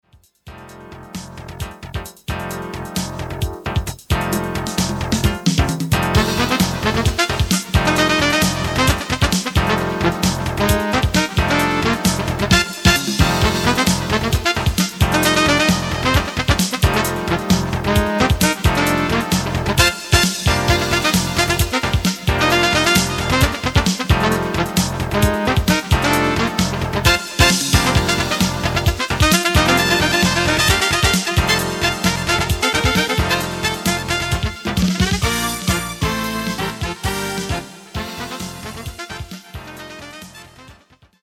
Writing of modern style Jazz.